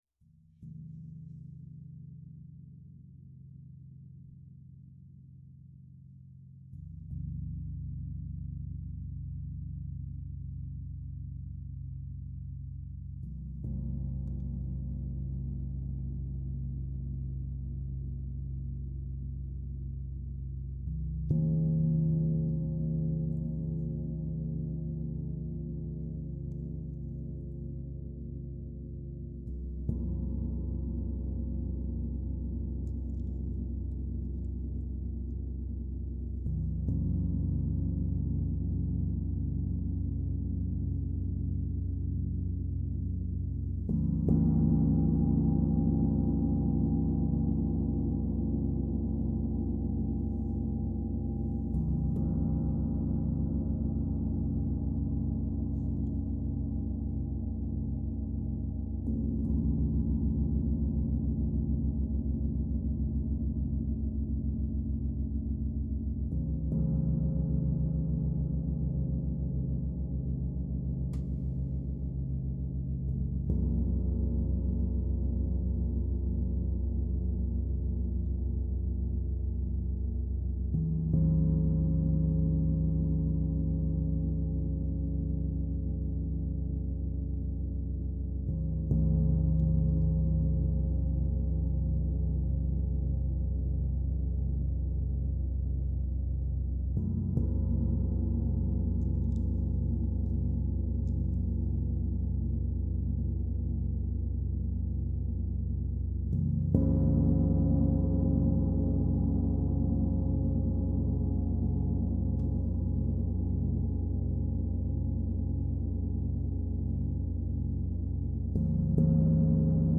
This is frequency medicine powerful, deep, and designed to clear space for transformation.
38 inch Symphonic Gong
Gong-15mins.mp3